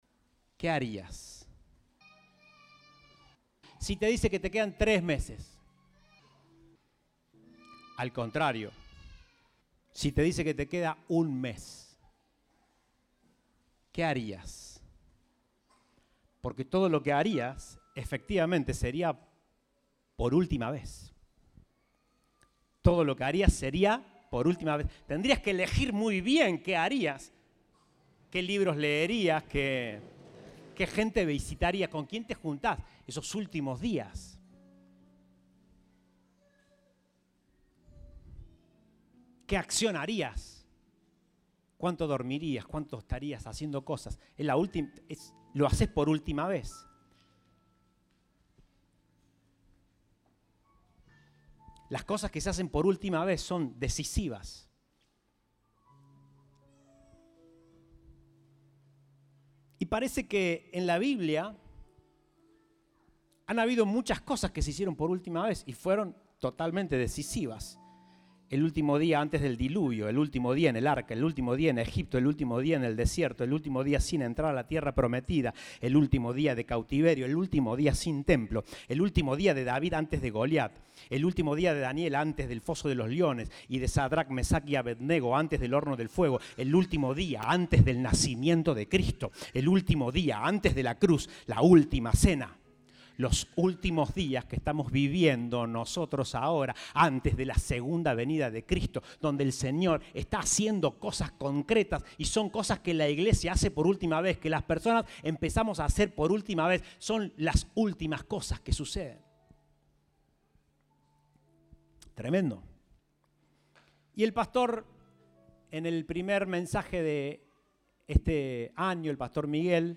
Compartimos el mensaje del Domingo 29 de Enero de 2023